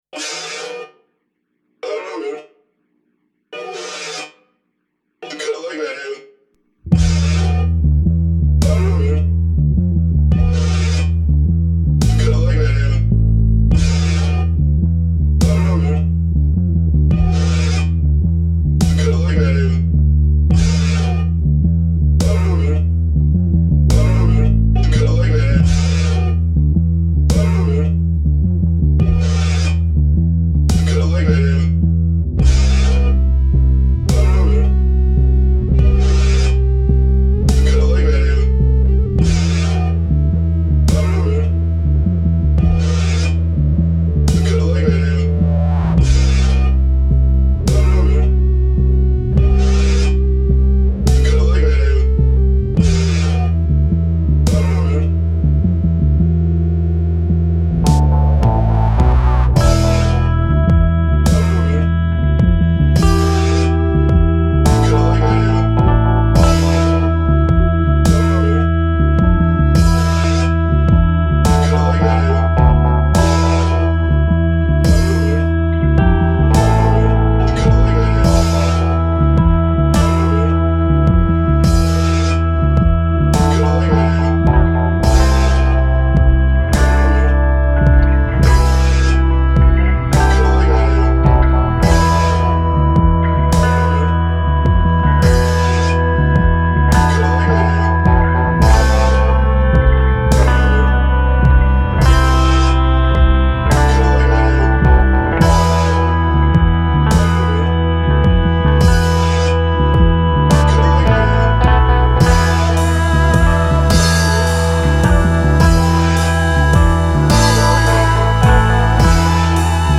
vocals and bass
Numbered 7″ Single